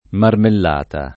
[ marmell # ta ]